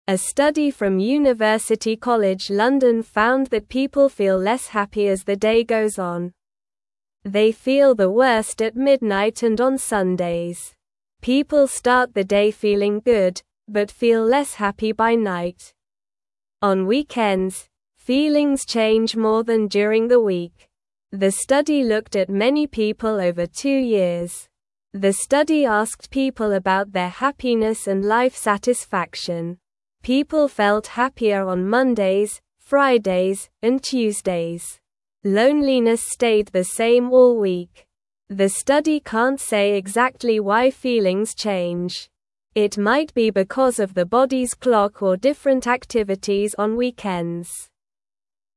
Slow
English-Newsroom-Beginner-SLOW-Reading-Why-People-Feel-Happier-at-the-Start-of-Days.mp3